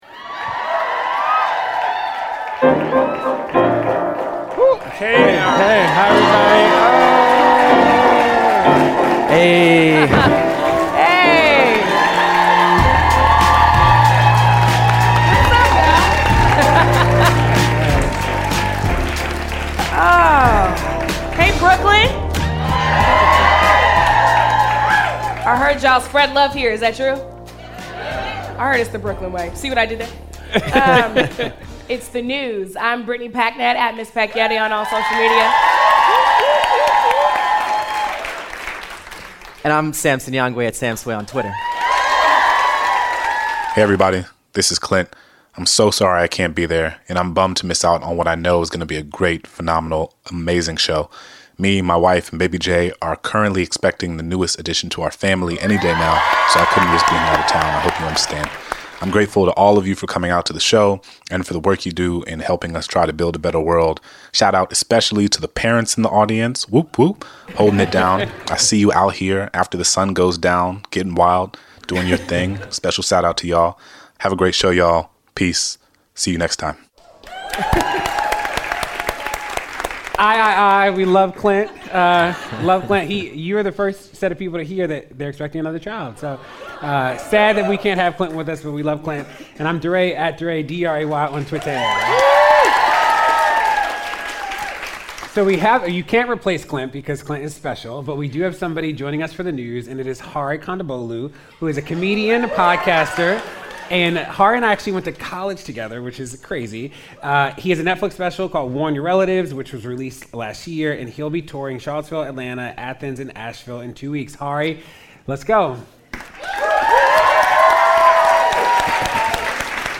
Early Polling (LIVE from Brooklyn, NY)